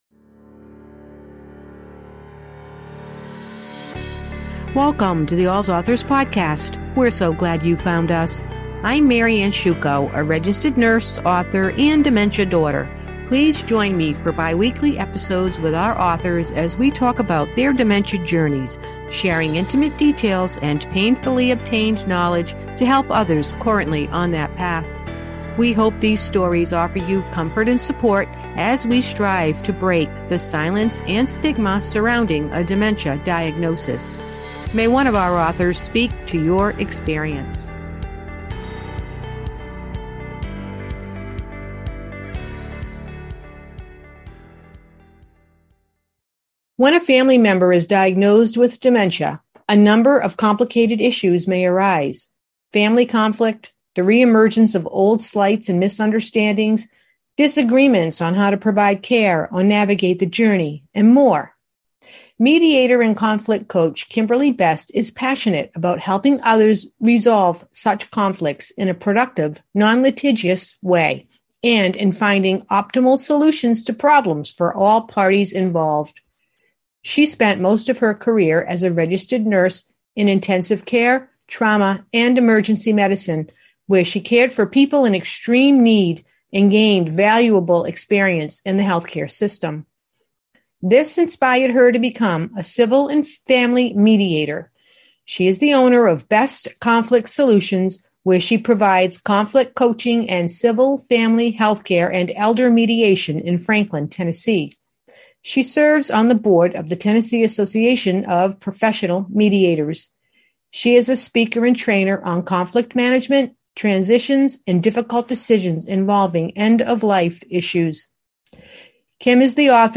In each episode, we interview one of our authors who may have written a memoir, caregiver guide, novel, children’s book, activity guide, poetry book, or blog.